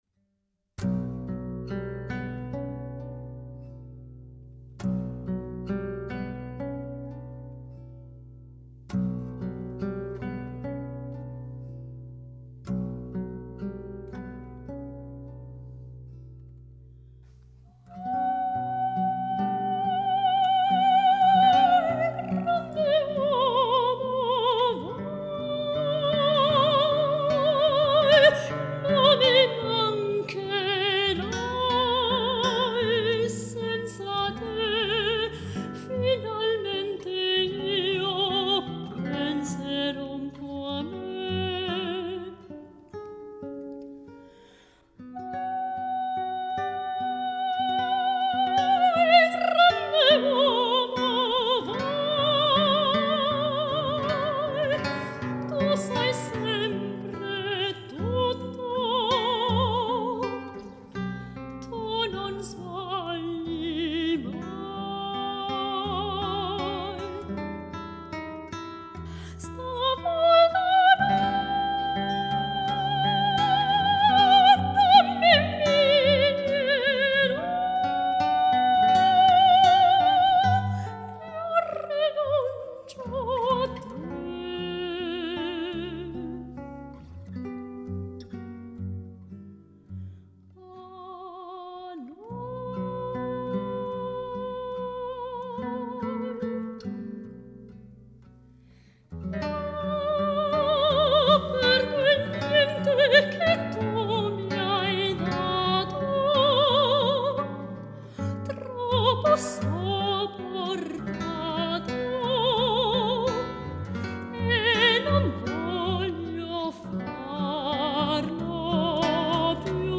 Lieder und Arien
Gitarre